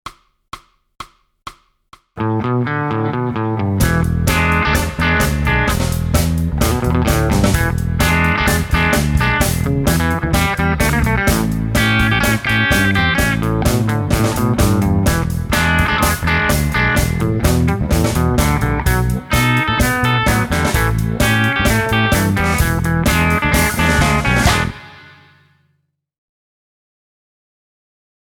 Guitare Tablatures